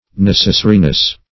\Nec"es*sa*ri*ness\